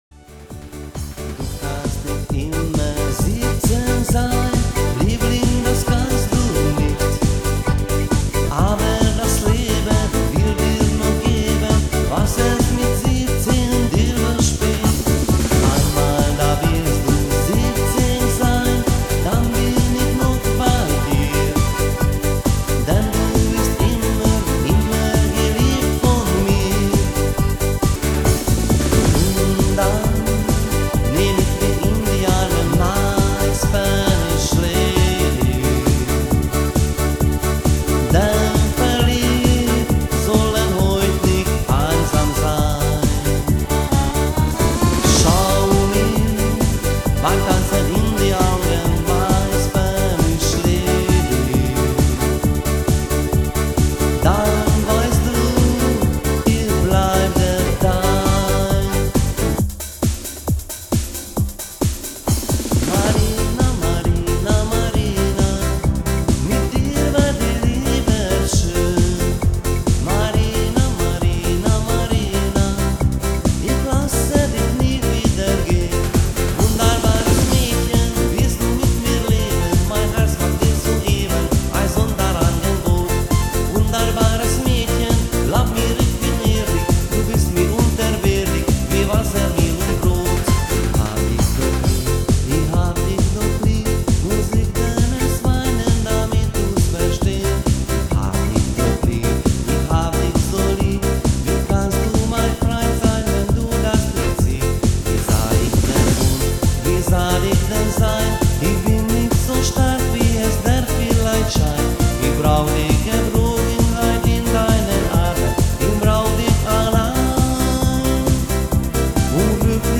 Programm mit meinem Gesang